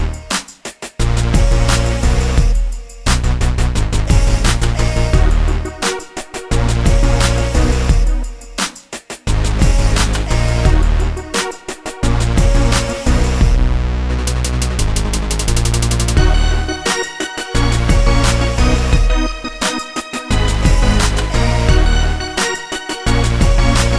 No Female Backing Vocals R'n'B / Hip Hop 3:43 Buy £1.50